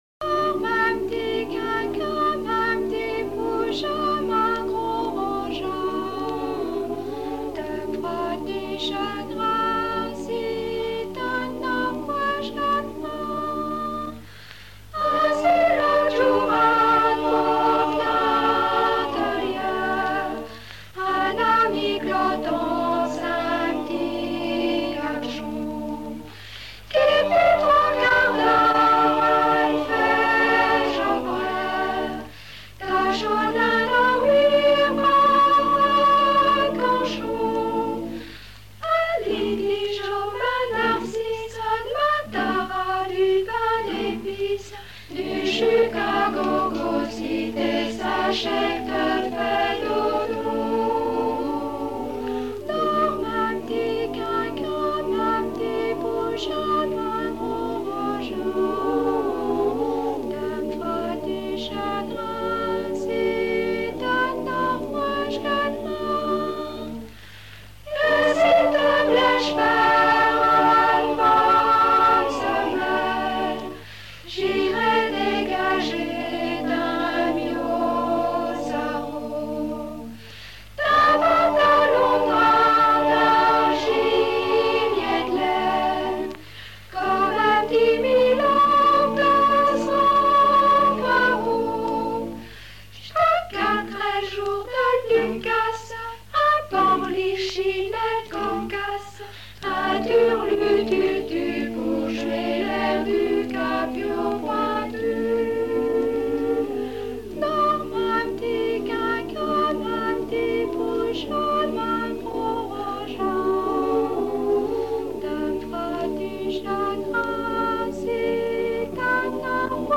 enfantine : berceuse
Présentation du musée du château de Noirmoutier
Pièce musicale inédite